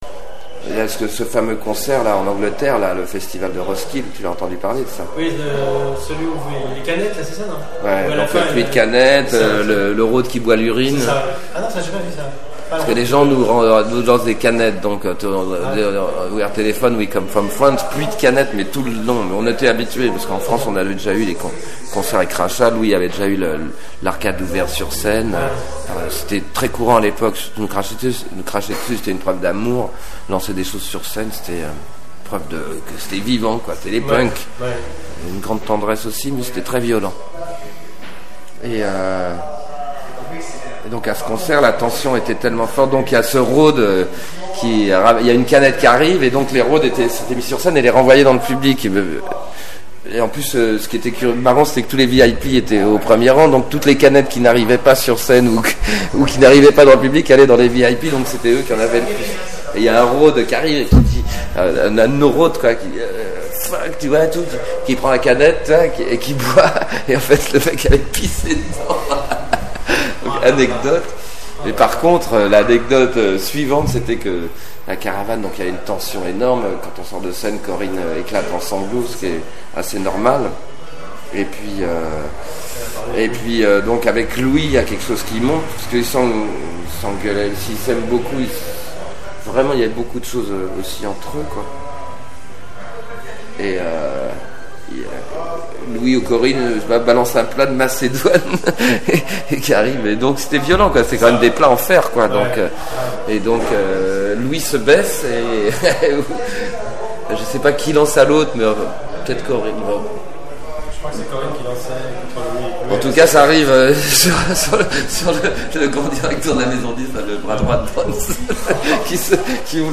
Voici quelques extraits d'interviews de Jean-Louis Aubert menées pour la biographie de Téléphone...